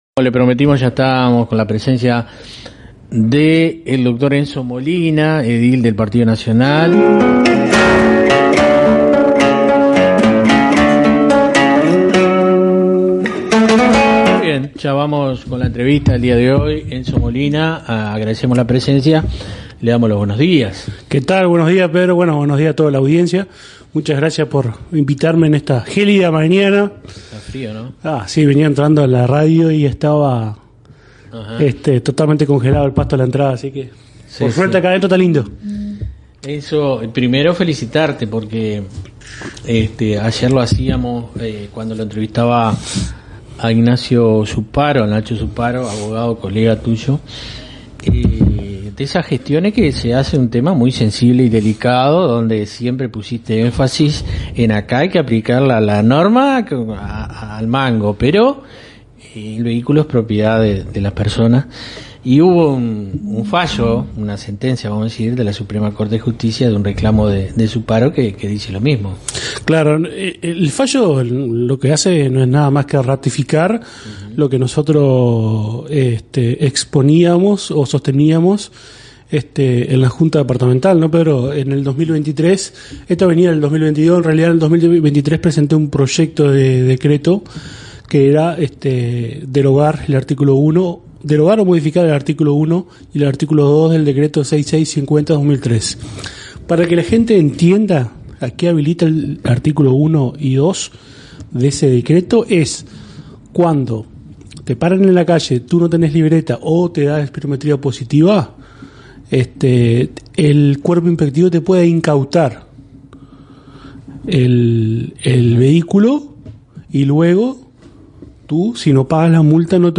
En una entrevista realizada por Cero Estrés Radio, el edil Dr. Enzo Molina abordó con claridad y firmeza el escenario político y administrativo que se abre tras el cambio de gobierno departamental en Salto. Lejos de discursos vacíos, Molina planteó lo que muchos piensan pero pocos se animan a decir: la transición ha sido empañada por decisiones apresuradas y poco transparentes que comprometen el futuro de todos los salteños.